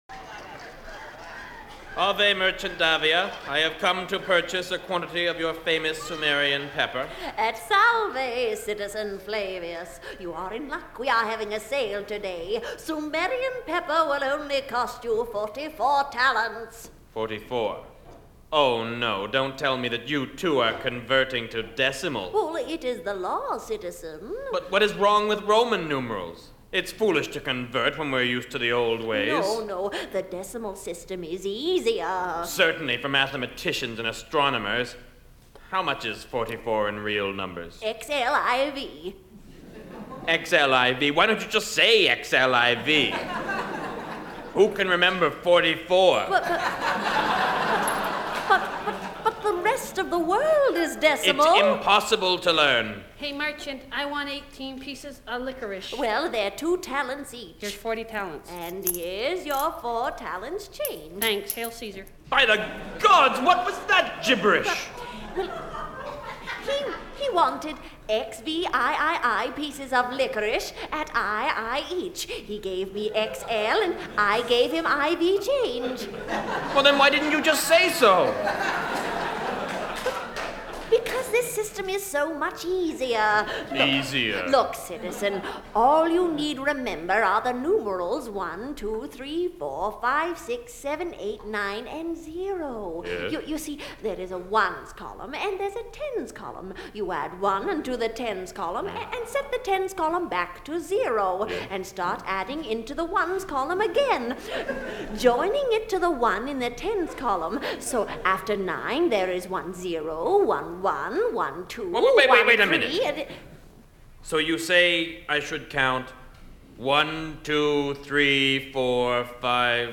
The Frantics were a sketch comedy group that aired on CBC Radio in the